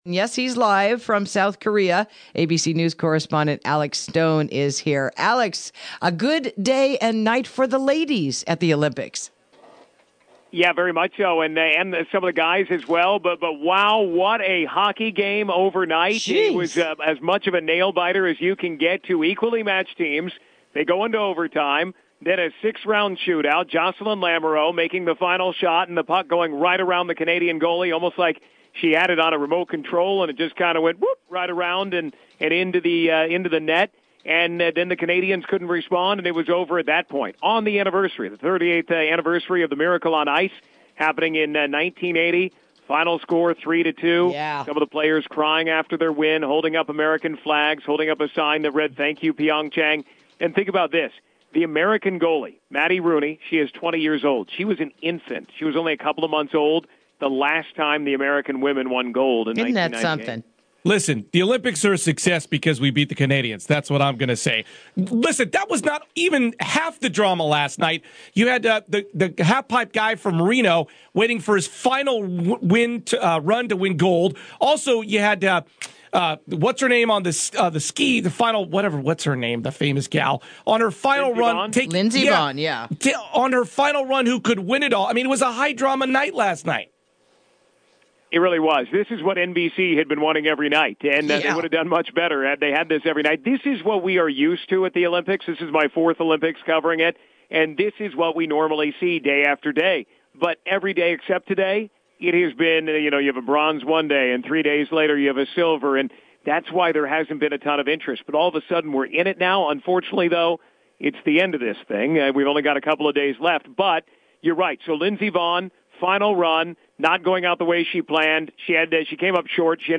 Interview: Team USA Comeback at the 2018 Winter Olympics?